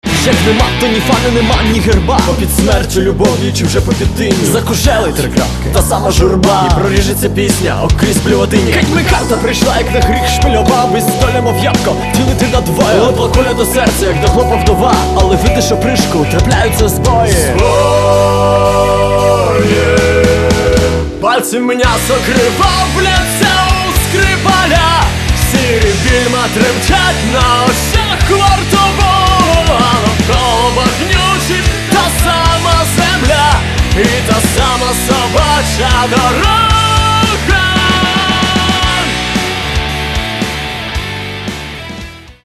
Каталог -> Рок и альтернатива -> Энергичный рок